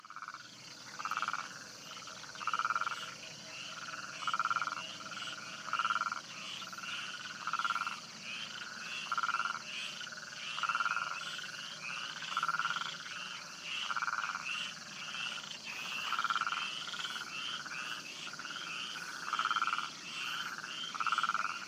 Chant
séquence sonore enregistrée au Maroc, sud de Ouarzazate dans le lit d'un oued. En arrière plan quelques Hyla meridionalis
chant-mauritanicus-maroc.mp3